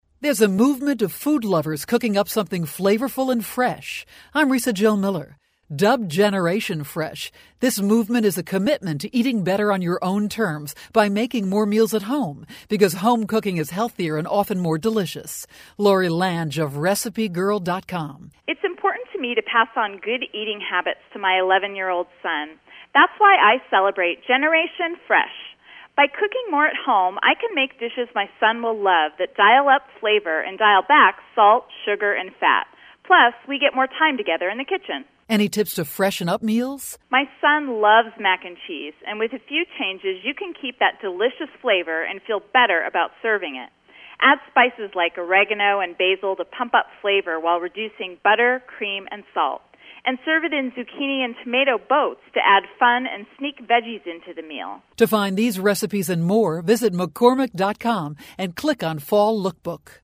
October 19, 2012Posted in: Audio News Release